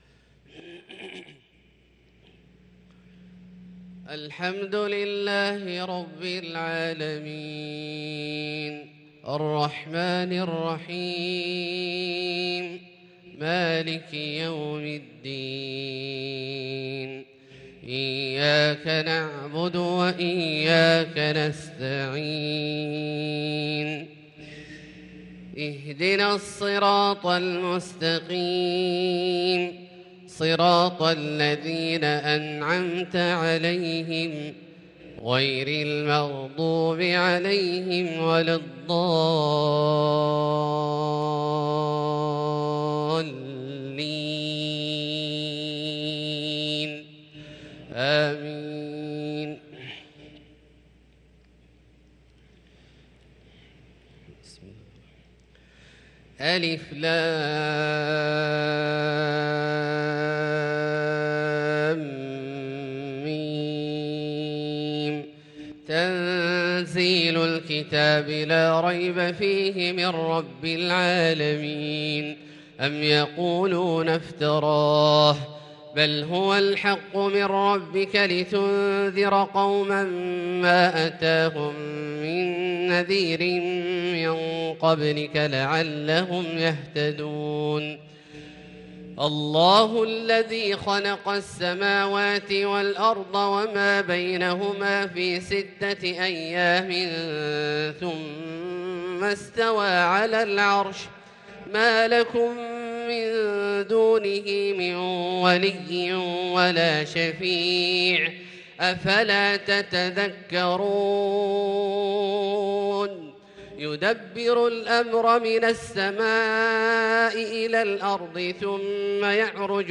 صلاة الفجر للقارئ عبدالله الجهني 17 ذو القعدة 1443 هـ
تِلَاوَات الْحَرَمَيْن .